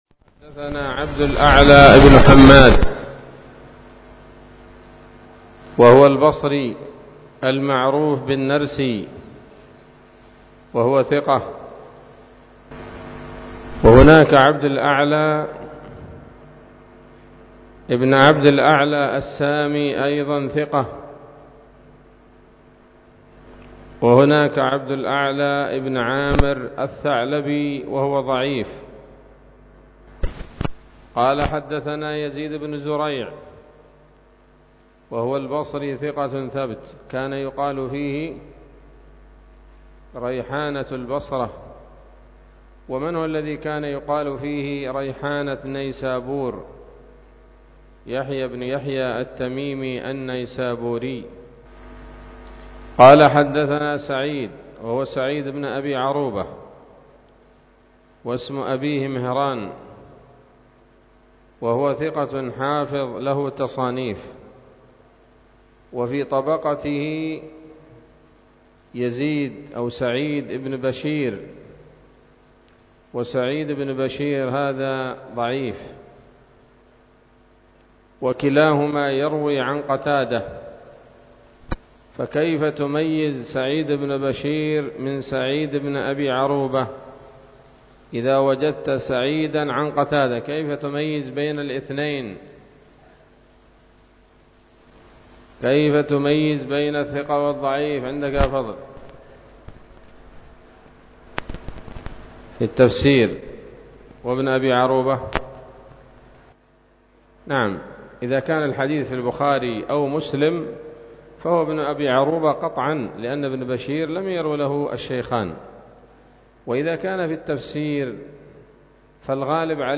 الدرس الثاني والثمانون من كتاب النكاح من صحيح الإمام البخاري